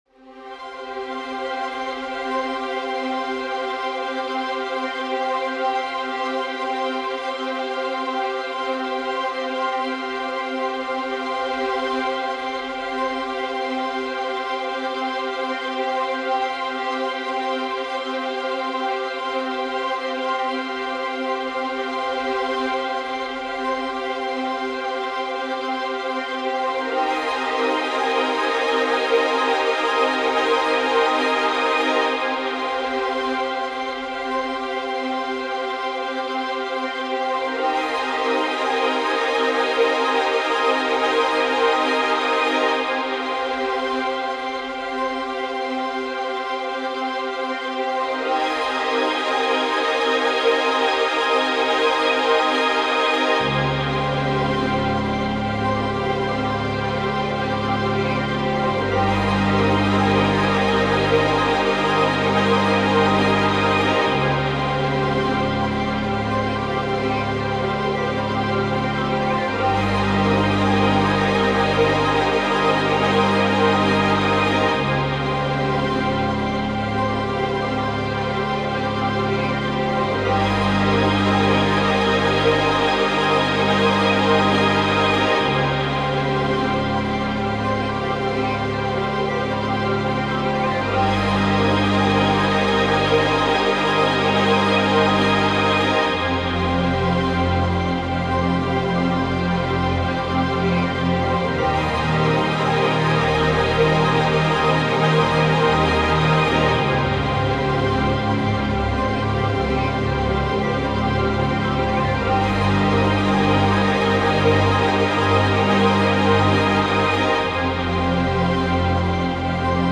Malevolent, shimmering sprites in great spaces.